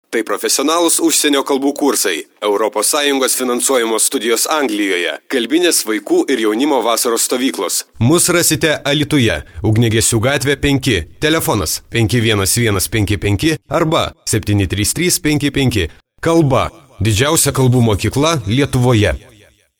Sprecher litauisch für TV / Rundfunk / Industrie.
Professionell voice over artist from Lithuania.